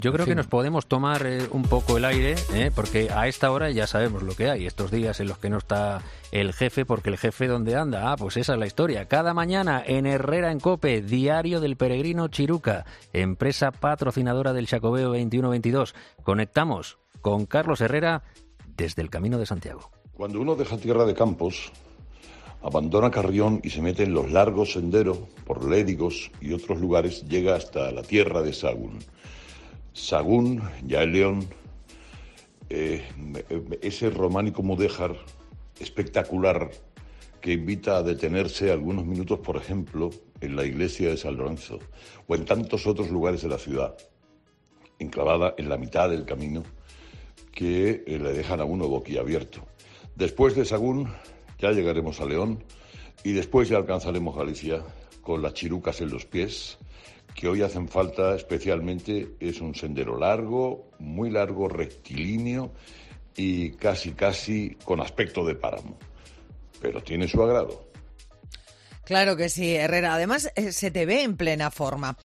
Este miércoles, como cada día, el propio Herrera ha intervenido en el programa para actualizar a los oyentes sobre cómo va su peregrinaje hasta Santiago de Compostela.